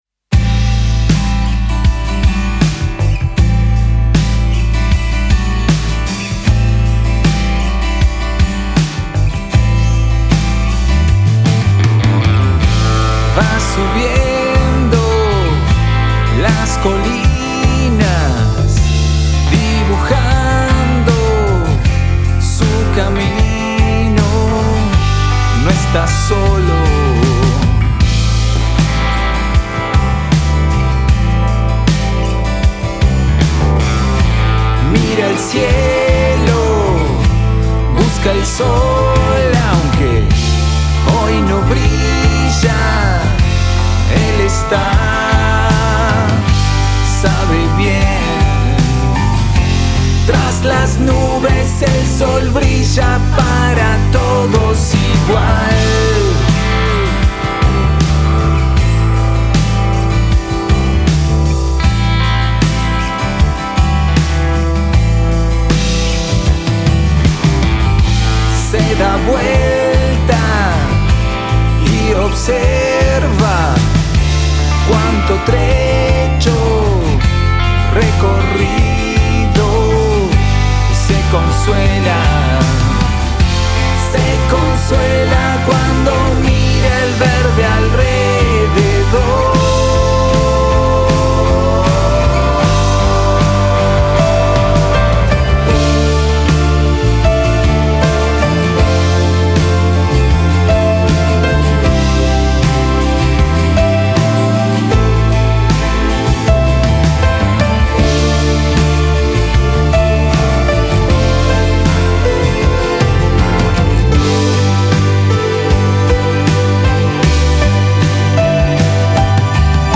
batería
bajo
hammond
guitarra, piano y voz